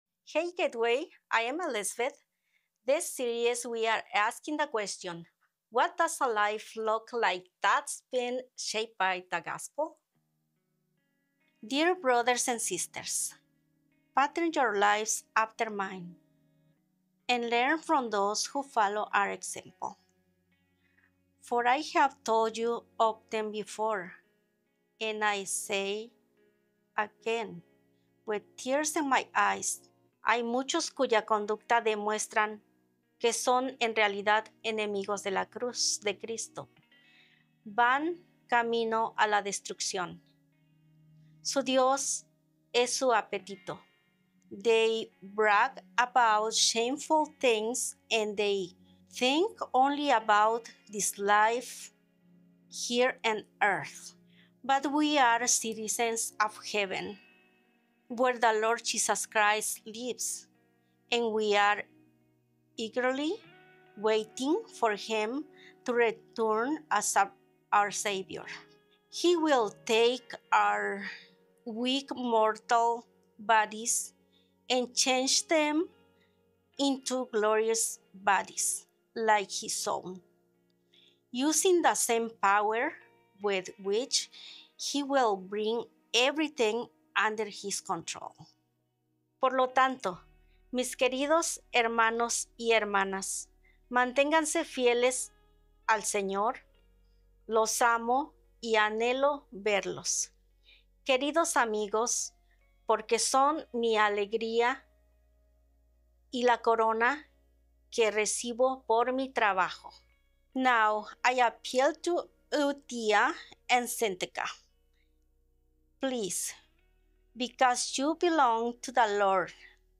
Healthy-Kingdom-Living-Sermon-6.15.m4a